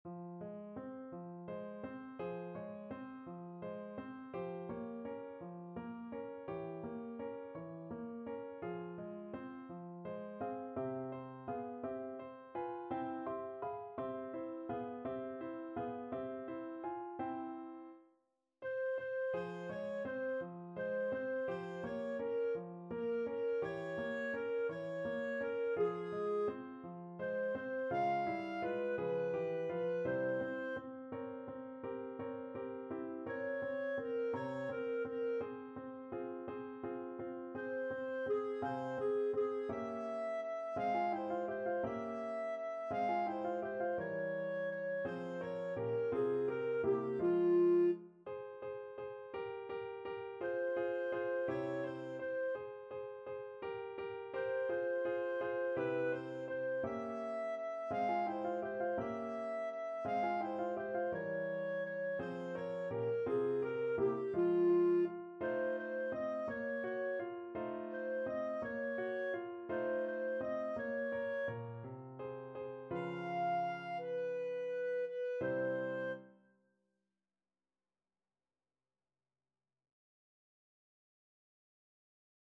. = 56 Andante
6/8 (View more 6/8 Music)
Classical (View more Classical Clarinet Music)